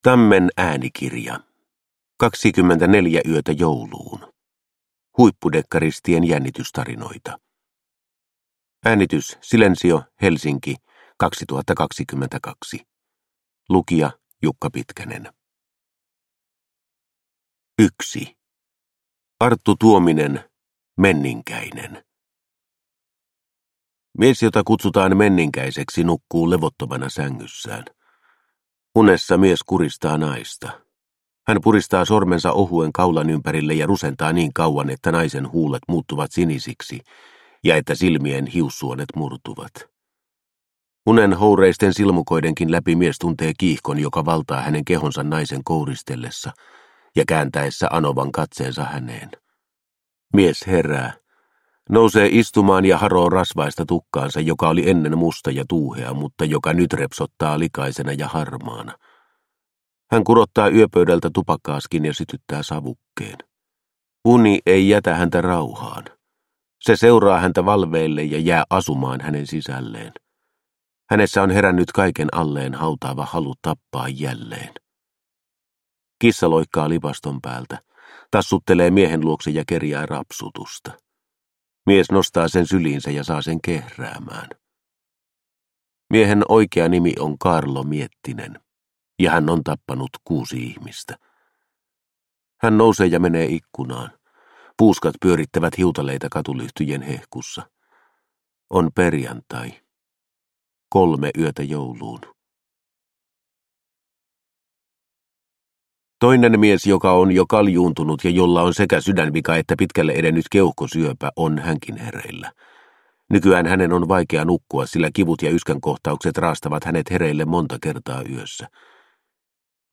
24 yötä jouluun – Ljudbok – Laddas ner